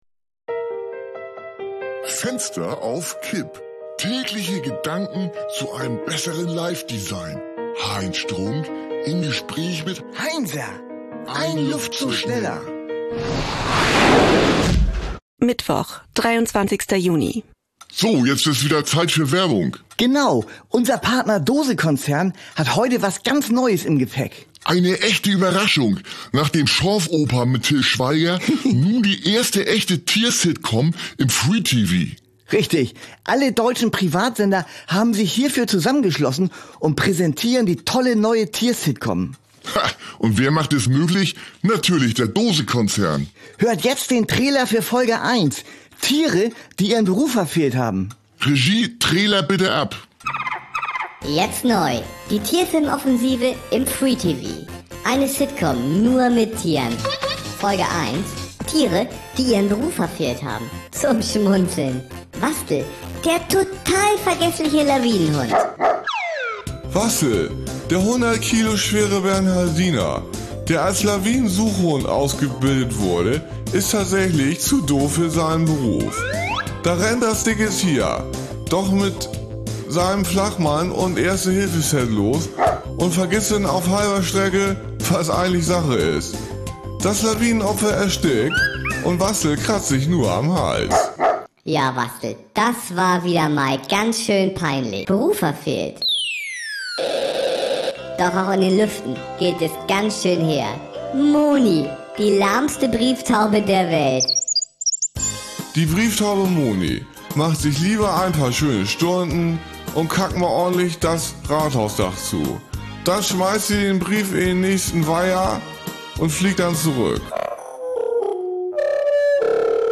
eine Audio-Sitcom von Studio Bummens